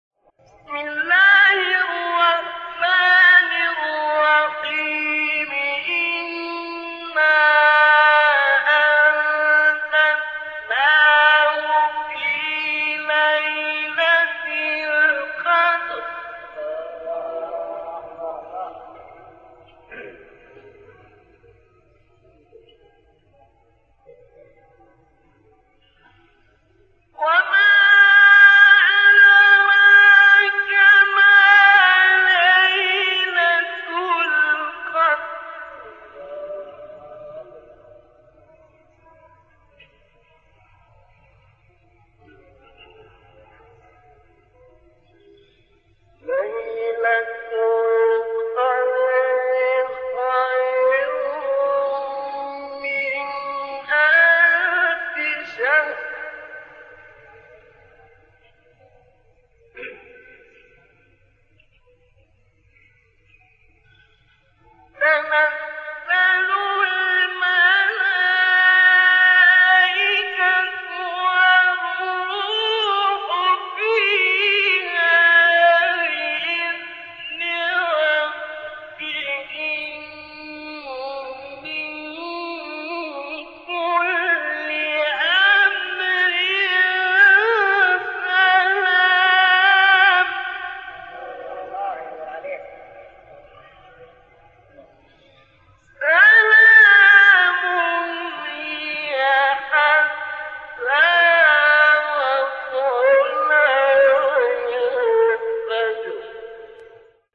آیه 1-5 سوره قدر استاد شعیشع | نغمات قرآن | دانلود تلاوت قرآن